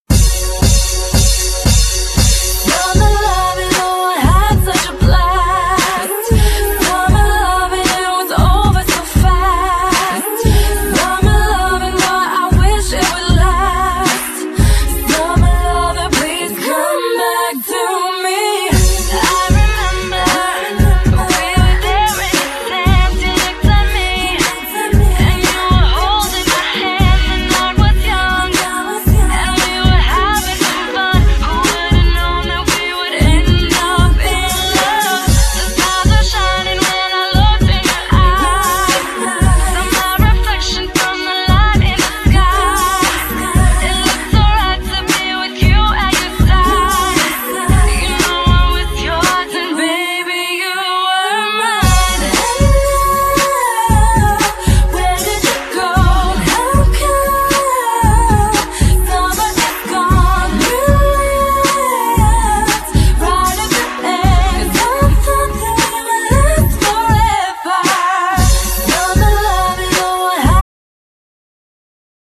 Genere : Pop
cantato con il rapper milanese